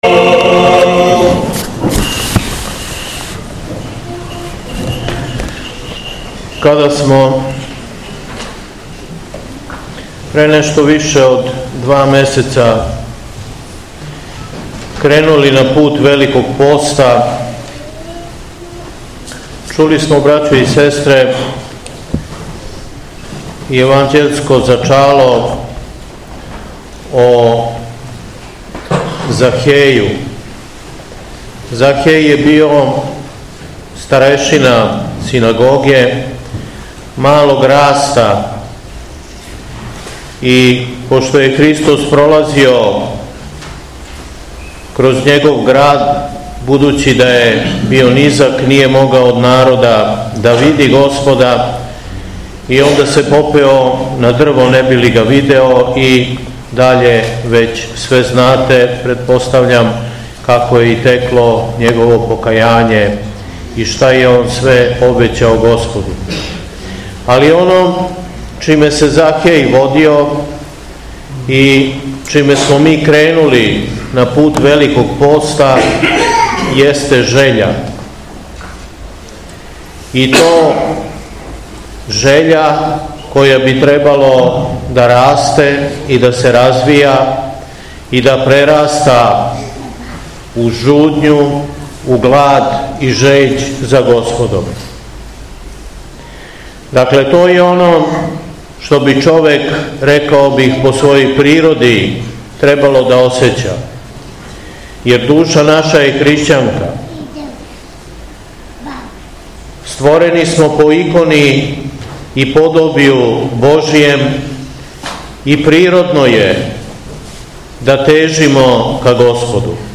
ЦВЕТИ У СТАРОЈ ЦРКВИ У КРАГУЈЕВЦУ - Епархија Шумадијска
Беседа